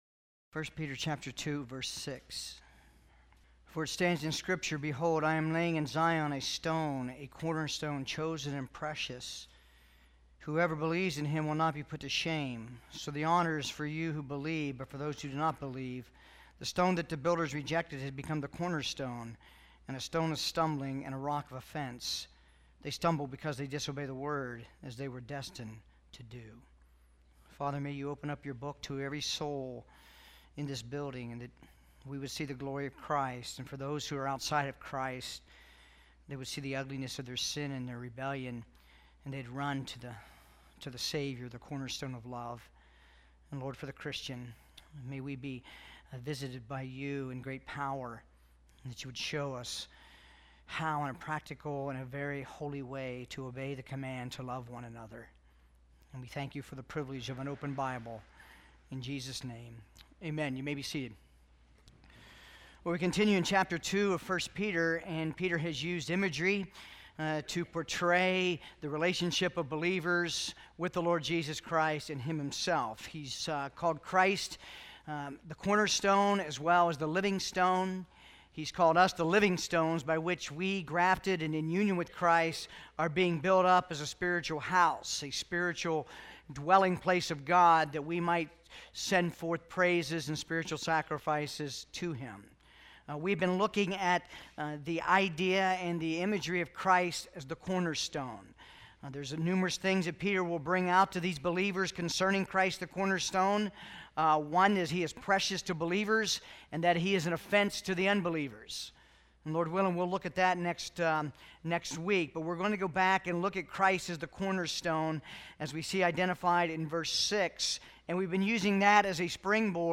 This sermon is second part of a look at how we are to love one another and thus build our lives and church on the Cornerstone of Love, Jesus Christ.